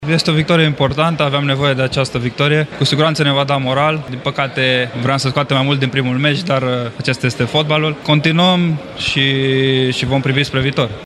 Unul dintre principalii protagoniști ai serii, arădeanul Dennis Man, a vorbit despre importanta victoriei cu Cipru: